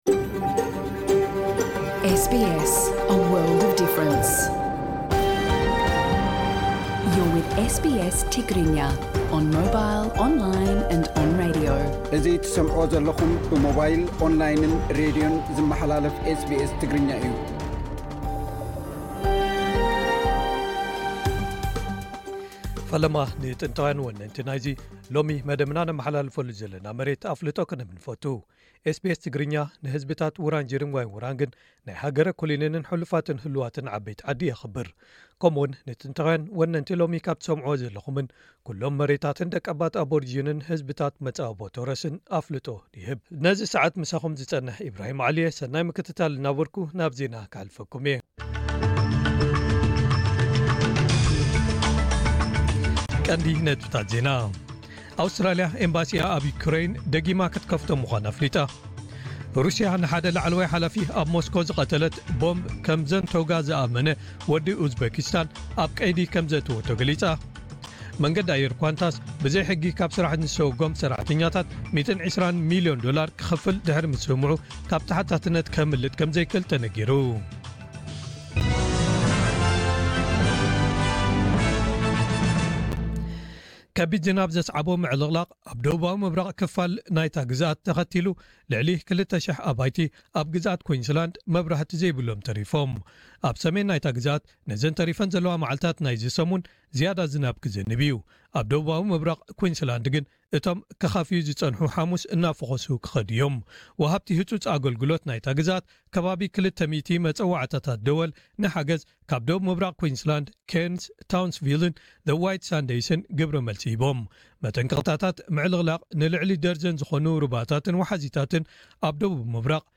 ነዚ ዝምልከት ትሕዝቶ * ቃለመሕትት - ኣብ ዝሓለፉ ሒደት ዓመታት መንግስቲ ግዝኣት ቪክቶርያ ነቶም ብዙሓት ካብ ኣባላት ማሕበረሰብ ኤርትራ፡ ኢትዮጵያን ካልኦትን ዝነብሩሎም ኣብ 60ታት ዝተሃንጹ ህዝባውያን ሰማይ ጠቀስ ህንጻታት ከፍርሶም መደብ ሒዙ ክሰርሓሉ ጸነሑ'ዩ።